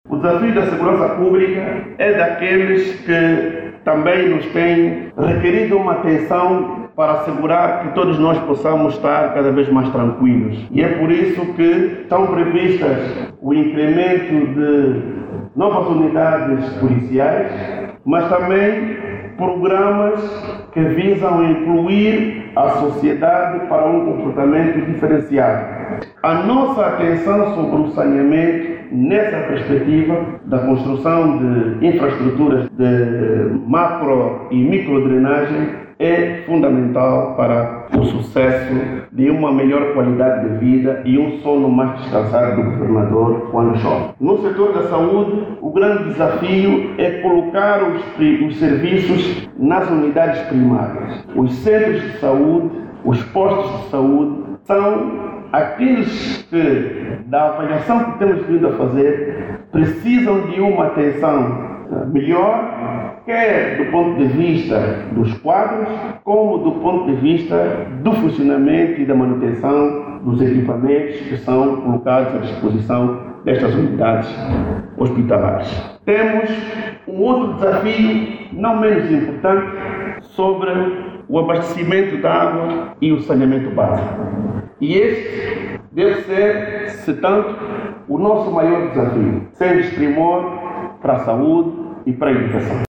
O dirigente fez esses pronunciamentos na Universidade Órcar Ribas numa palestra onde foi tema: os desafios da província de Luanda.
Oiça agora o aúdio do Governador.